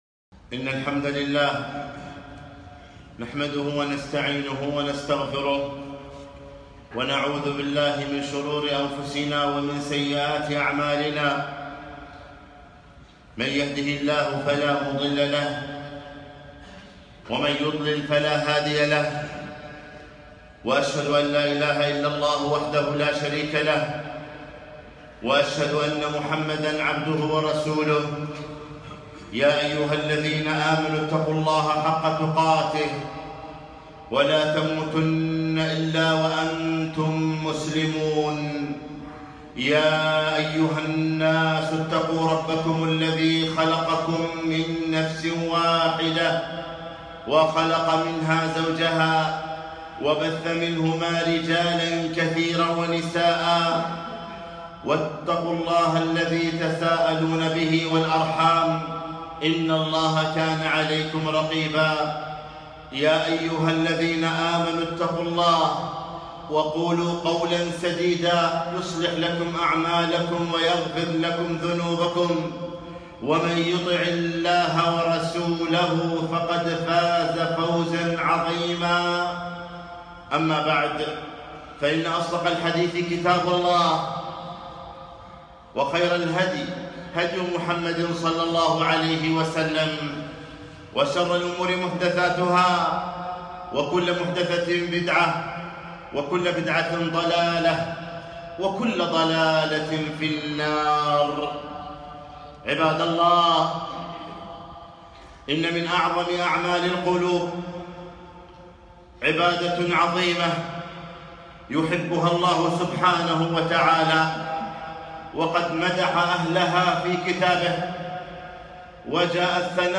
خطبة - وتوكل على الحي الذي لا يموت